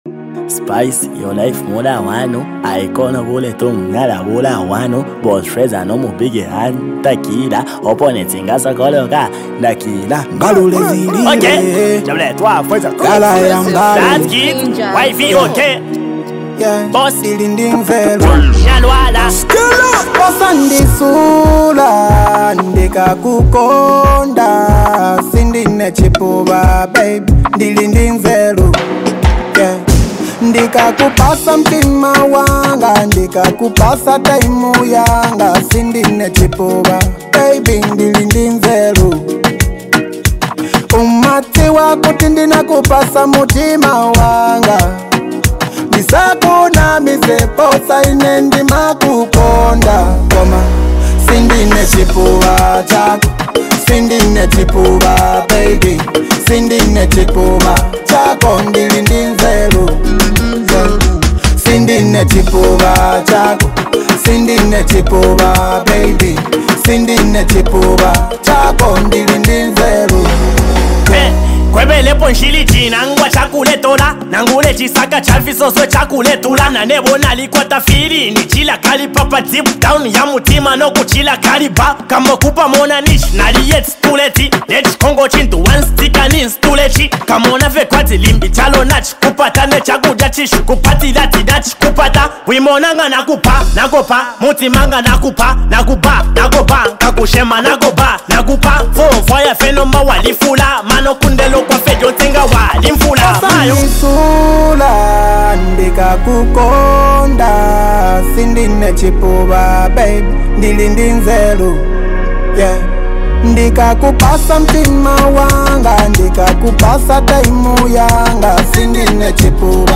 Highly multi talented act and super creative rapper